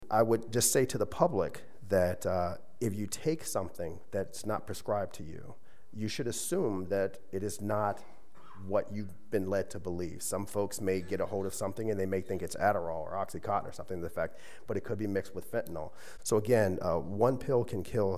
Director of RCPD Brian Peete spoke at Monday’s Law Board meeting.